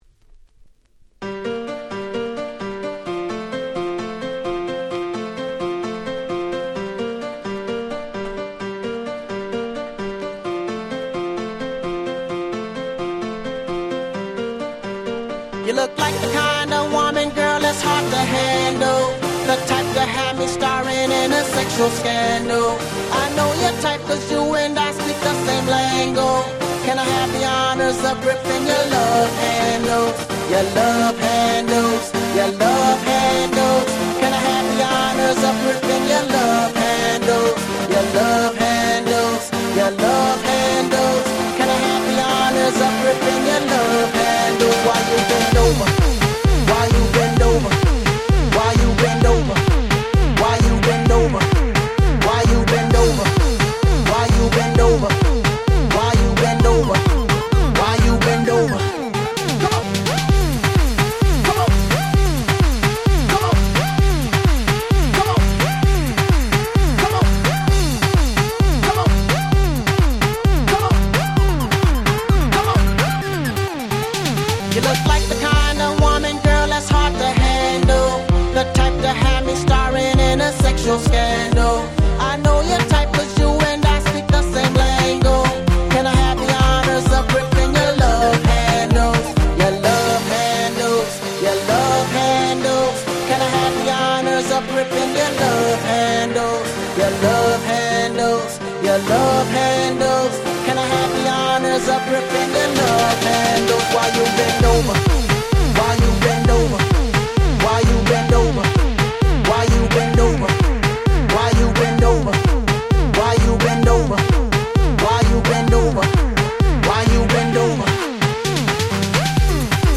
11' Super Hit EDM / R&B / Hip Hop !!